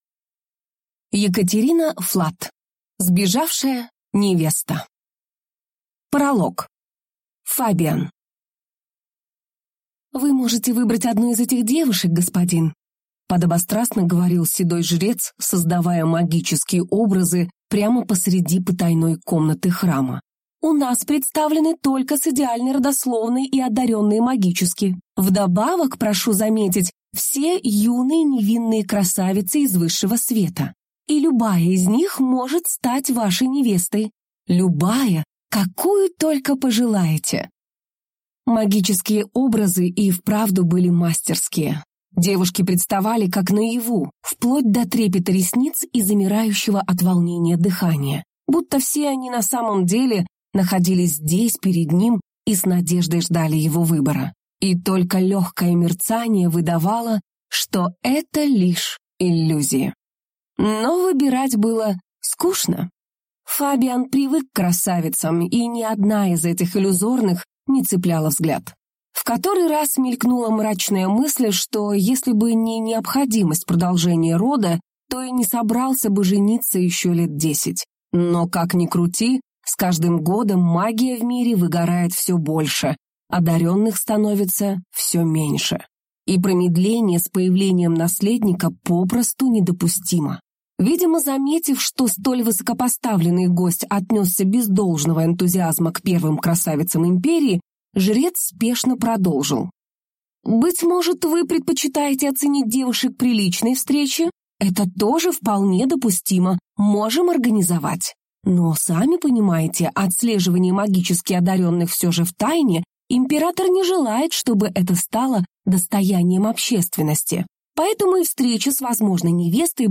Аудиокнига Сбежавшая невеста | Библиотека аудиокниг